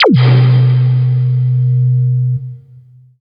84 BLIP   -R.wav